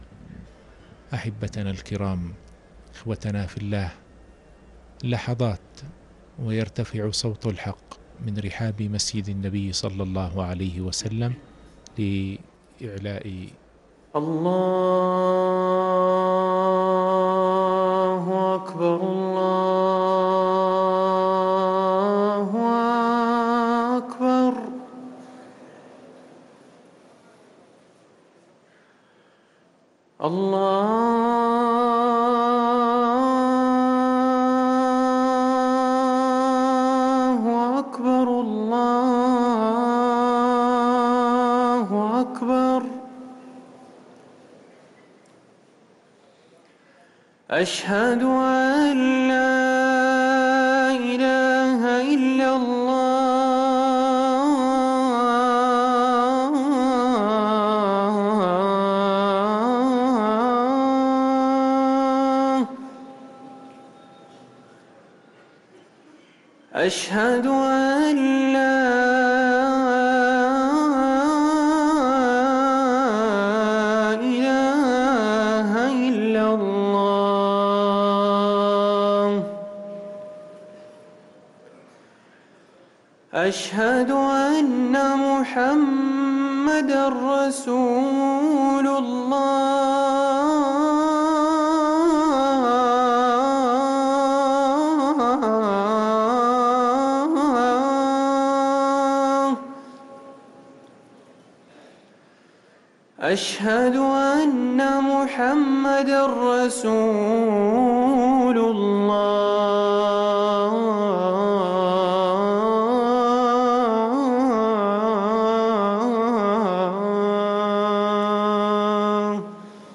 ركن الأذان 🕌